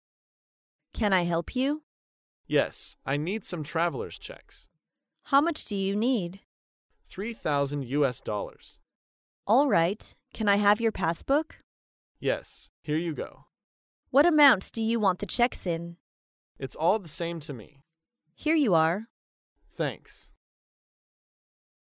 在线英语听力室高频英语口语对话 第422期:兑换旅行支票的听力文件下载,《高频英语口语对话》栏目包含了日常生活中经常使用的英语情景对话，是学习英语口语，能够帮助英语爱好者在听英语对话的过程中，积累英语口语习语知识，提高英语听说水平，并通过栏目中的中英文字幕和音频MP3文件，提高英语语感。